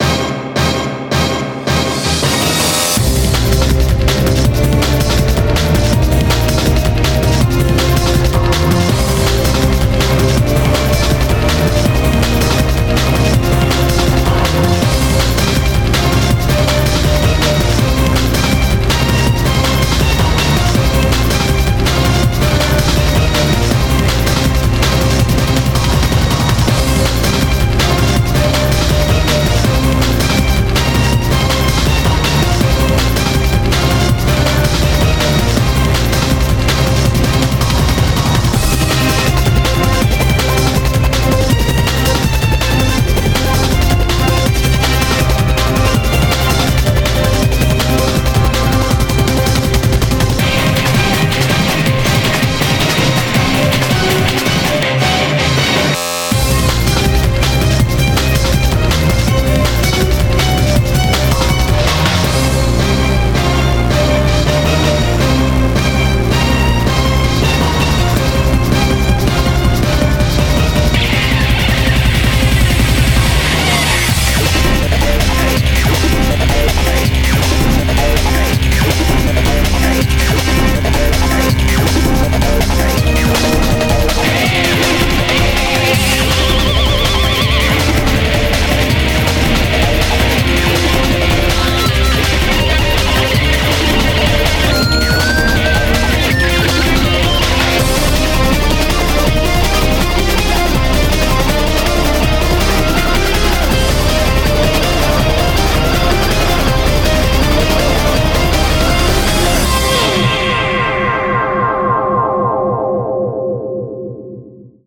BPM162
Audio QualityMusic Cut